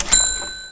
ching.wav